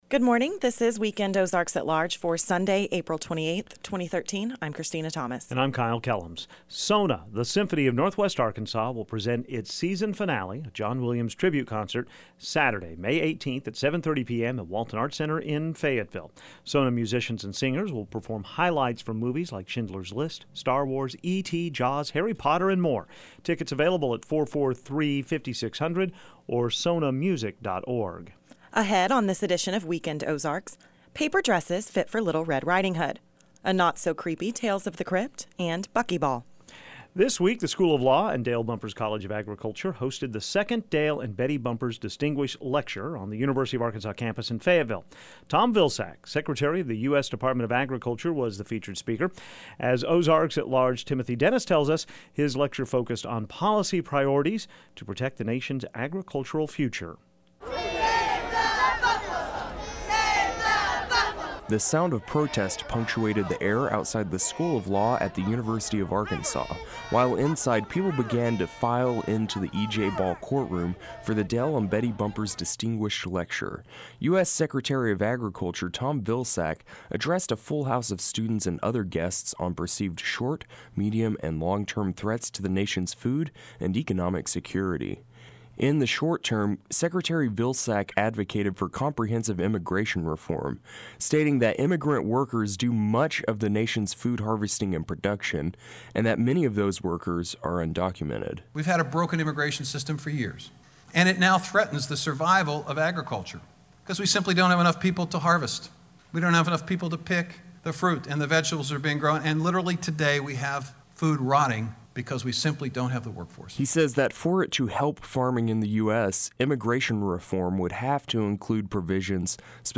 Tom Vilsack, the country's Secretary of Agriculture, was the esteemed speaker of yesterday's Dale and Betty Bumpers Distinguished Lecture at the University of Arkansas. He took the opportunity to speak candidly with the standing room only crowd about short-, medium-, and long-term ag public policy goals, and about opening lines of communication.